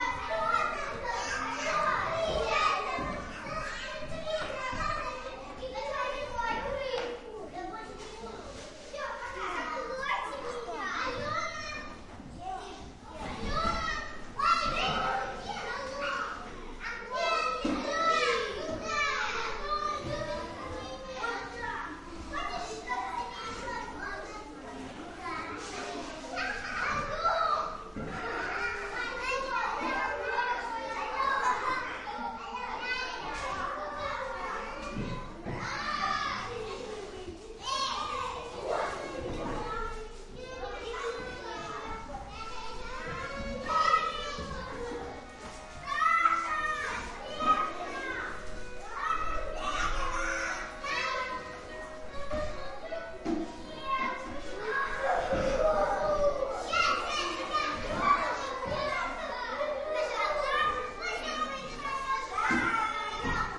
Звуки детского сада
Звуки игр и смеха российской детворы